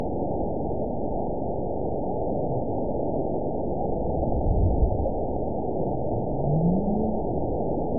event 917463 date 04/04/23 time 03:17:59 GMT (2 years, 1 month ago) score 9.03 location TSS-AB01 detected by nrw target species NRW annotations +NRW Spectrogram: Frequency (kHz) vs. Time (s) audio not available .wav